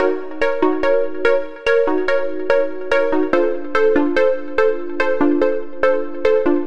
Tag: 144 bpm Trap Loops Synth Loops 1.12 MB wav Key : Unknown